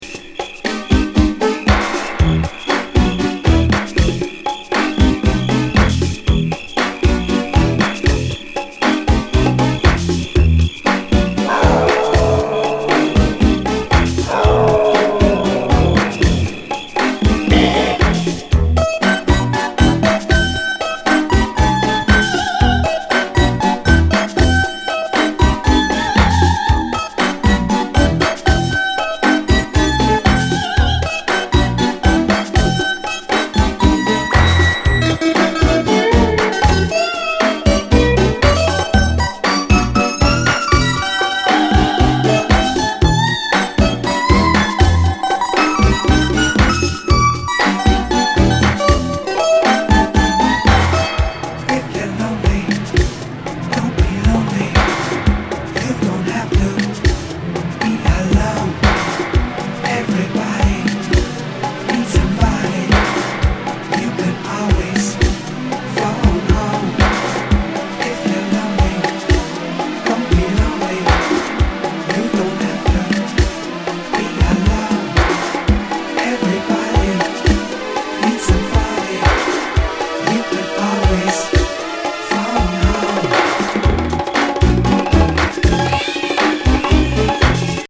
大好きチカーノ・レフティ・バレアリック！！